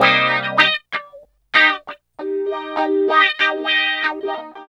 74 GTR 2  -R.wav